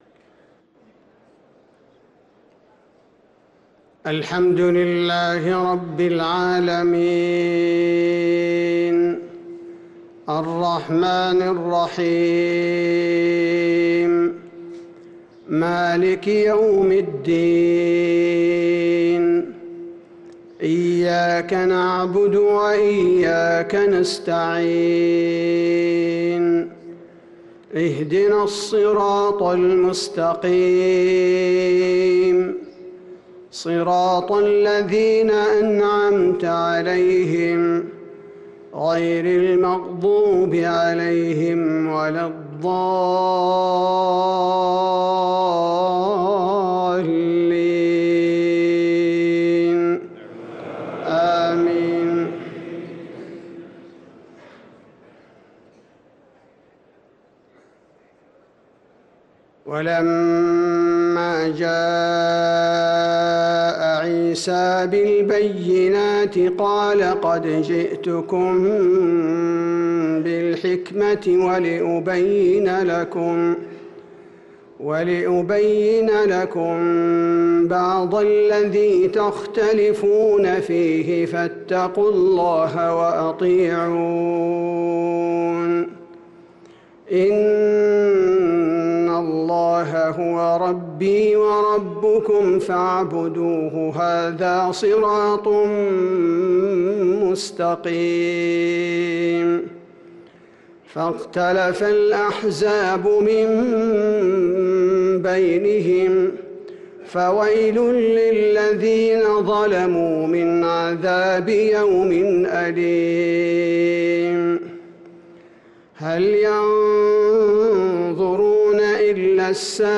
صلاة المغرب للقارئ عبدالباري الثبيتي 22 رجب 1445 هـ
تِلَاوَات الْحَرَمَيْن .